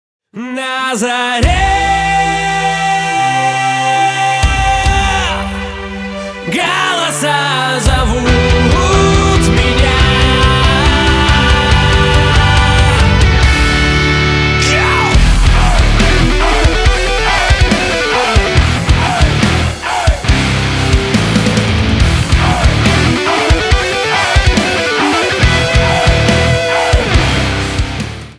Рок Металл
громкие # кавер